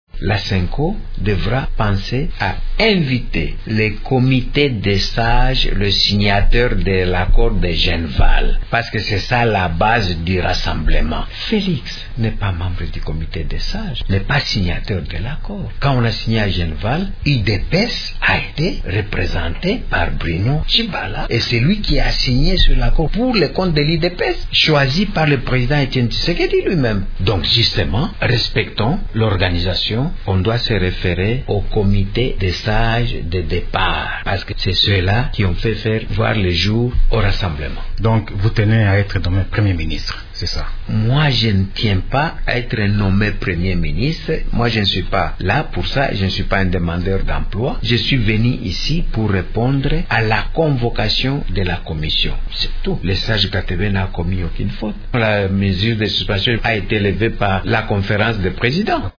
« Moi, je ne tiens pas à être nommé Premier ministre. Je ne suis pas là pour ça et je ne suis pas un demandeur d’emploi. Je suis venu ici pour répondre à la convocation de la commission. Le sage Katebe n’a commis aucune faute. La mesure de suspension a été levée par la conférence des présidents», a-t-il indiqué samedi 4 février dans une interview accordée à Radio Okapi.